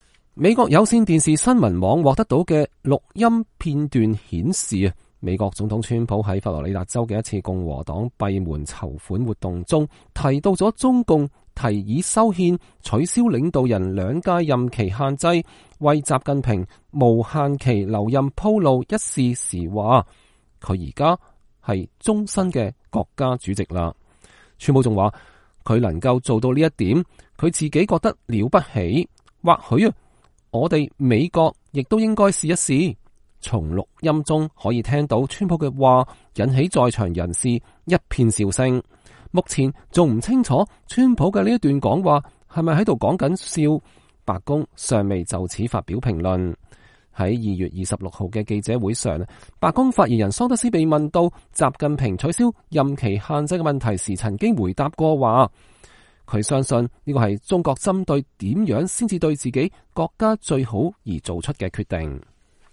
美國有線電視新聞網獲得的音訊顯示，美國總統川普在佛羅里達州的一次共和黨閉門籌款活動中提到了中共提議修憲，取消領導人兩屆任期限制，為習近平無限期留任鋪路一事時說：“他如今是終身國家主席了。”
川普還說：“他能做到這一點，我覺得了不起。或許哪天我們也該試試。”從錄音中可以聽到川普的話引起在場的人一片笑聲。